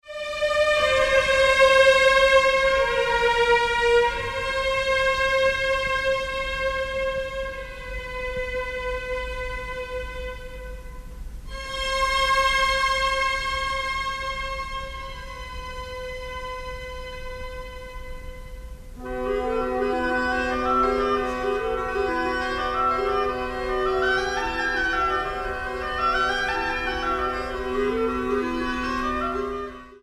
Genre: Classical
mezzo-soprano